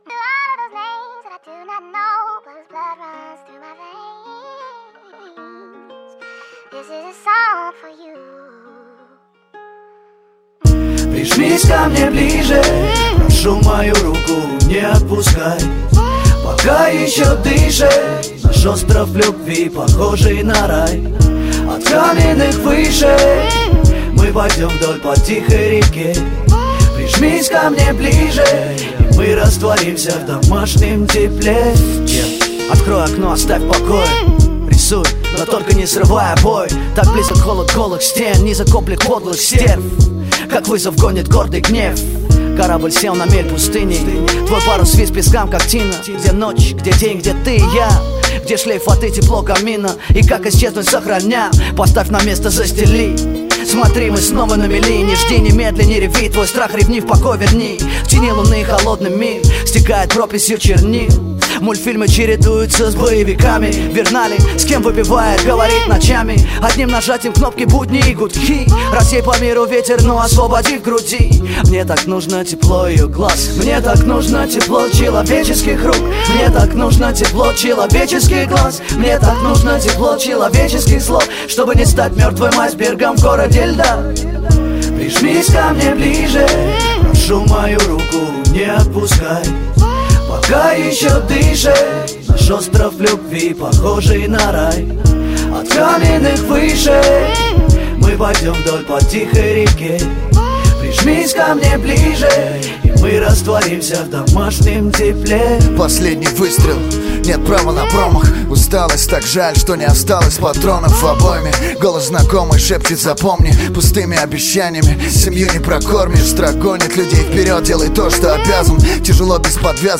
Русский рэп
Жанр: Русский рэп / Хип-хоп